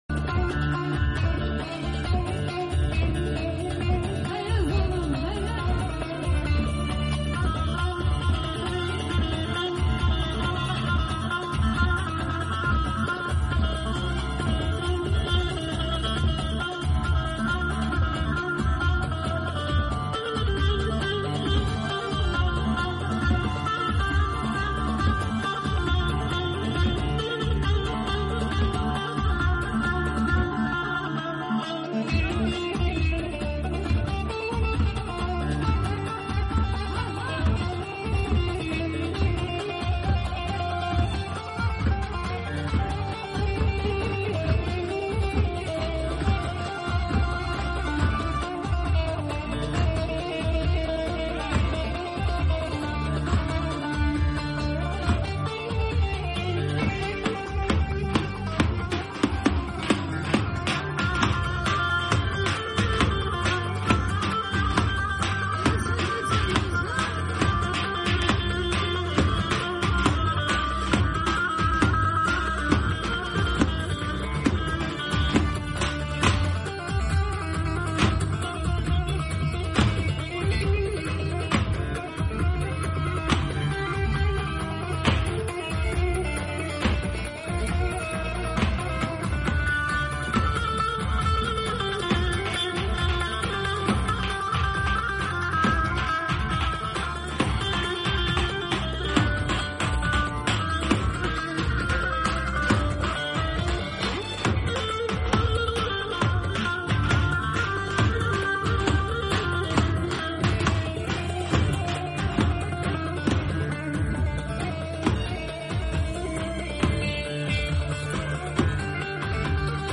Ş.urfa Halfeti Düğün Ağlayan Saz